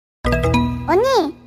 Nada notifikasi Unnie versi 1 Detik
Kategori: Nada dering
Keterangan: Lagi cari nada notifikasi WA yang simple tapi tetap cute ala Korea?
nada-notifikasi-unnie-versi-1-detik-id-www_tiengdong_com.mp3